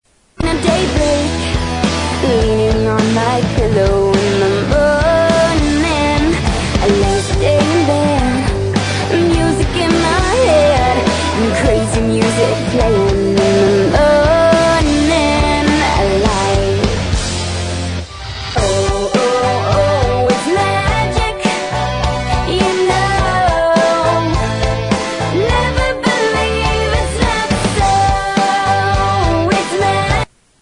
• Blues